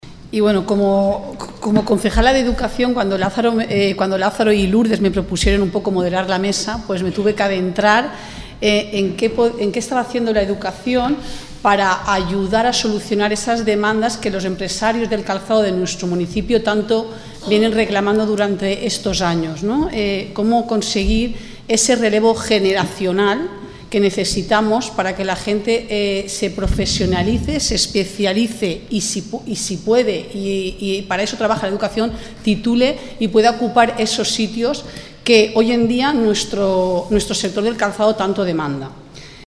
Interesante charla debate sobre empleabilidad y formación del sector del calzado
Silvia Verdú, edil de Educación ha sido la encargada de moderar la charla, donde en un primer momento se planteó “ que puede hacer la educación  para poder solucionar las demandas que los empresarios del calzado del municipio tanto vienen reclamando durante estos años”.